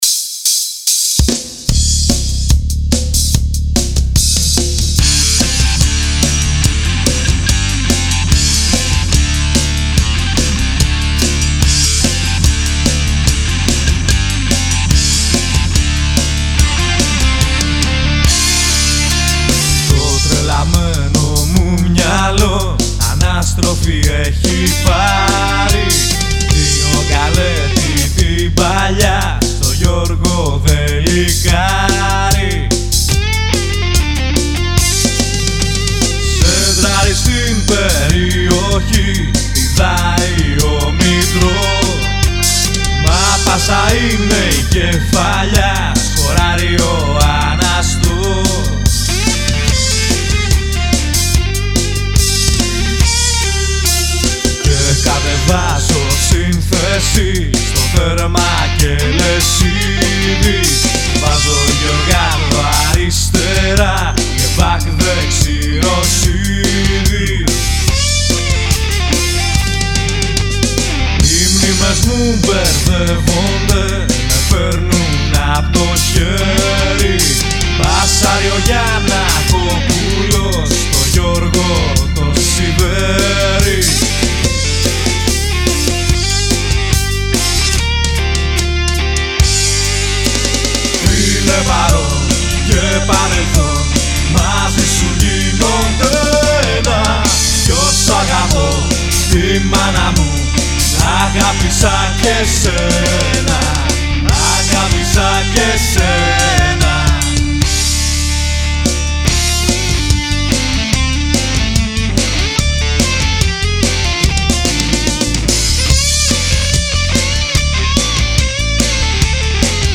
Το τραγούδι είναι γραμμένο στην κλίμακα ΡΕ μινόρε.
Lead & Power Guitar
Ντράμς
Πλήκτρα, Μπάσο